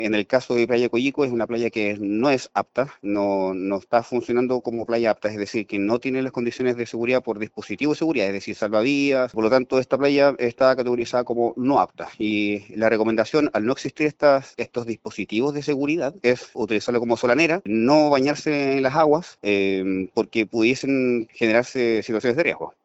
Así lo confirmó el Capitán de Puerto de Valdivia, Gonzalo Townsend, haciendo énfasis en que la playa de Collico no está apta para el baño por no contar con medidas de seguridad.